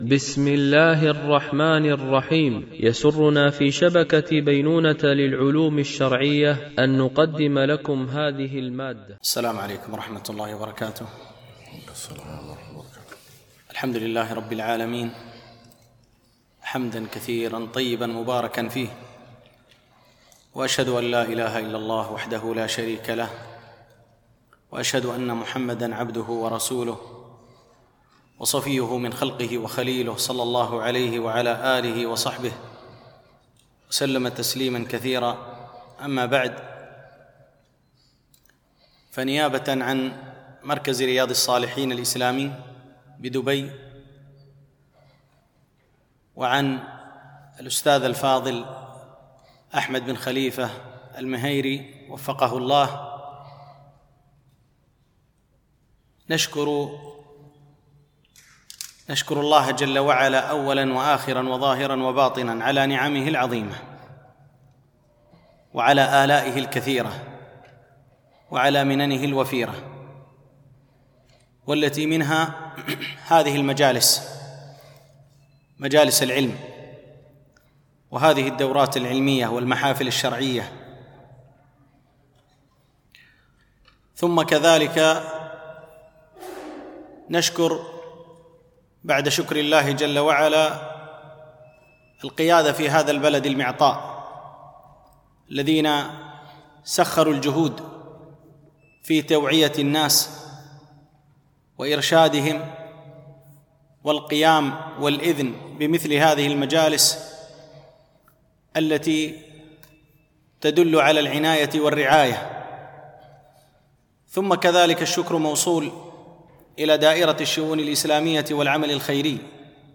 الدورة العلمية الثانية المترجمة للغة الإنجليزية، لمجموعة من المشايخ، بمسجد أم المؤمنين عائشة رضي الله عنها